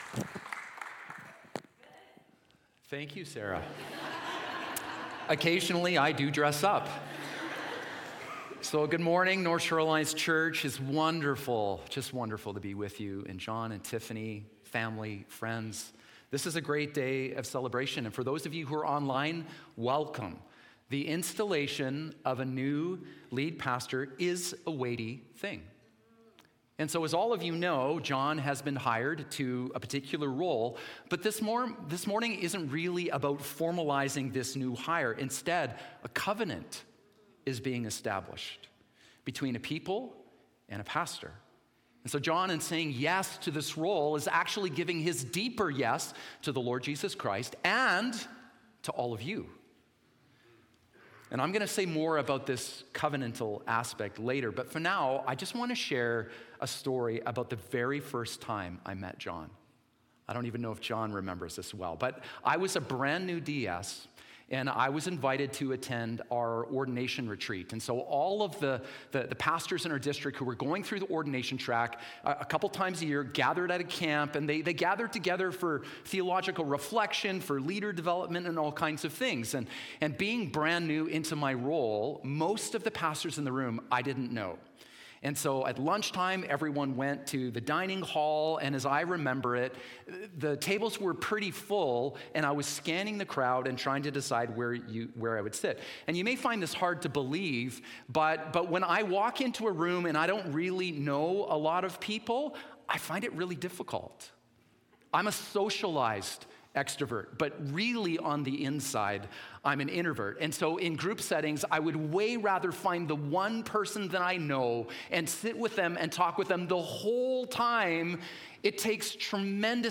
Installation Service